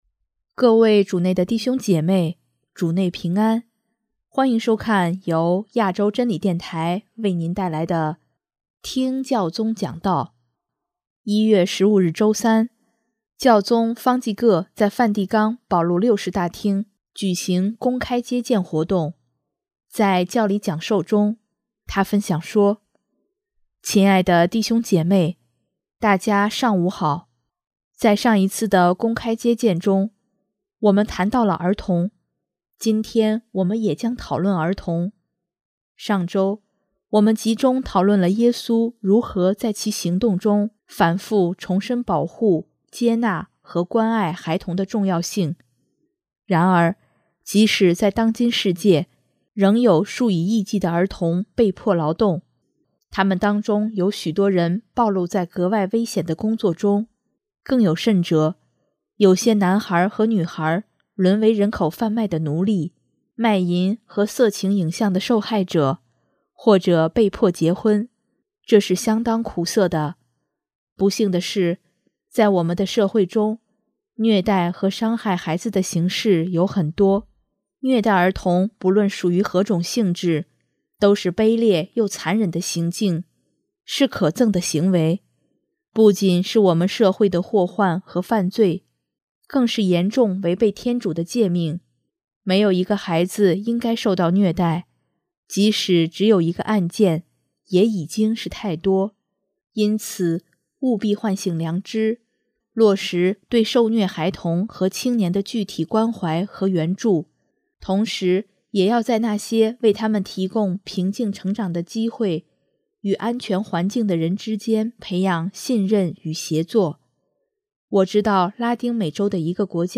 1月15日周三，教宗方济各在梵蒂冈保禄六世大厅举行公开接见活动，在教理讲授中，他分享说：